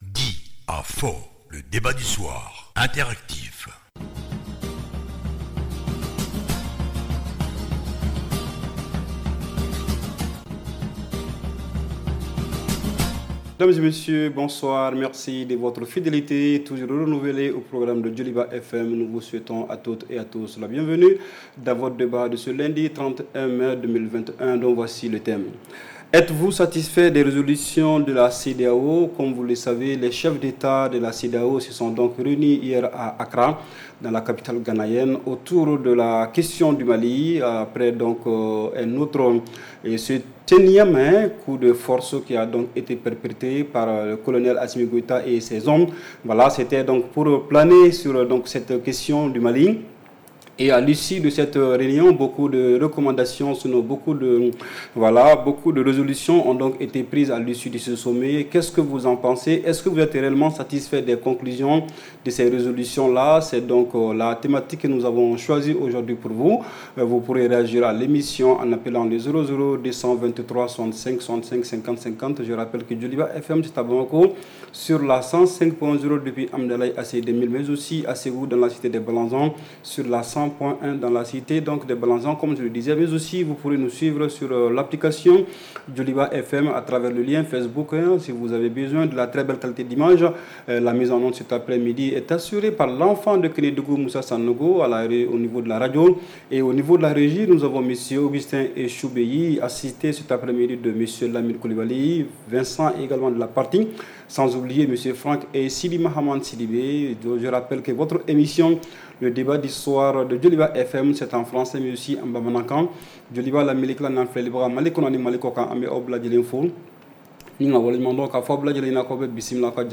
REPLAY 31/05 – « DIS ! » Le Débat Interactif du Soir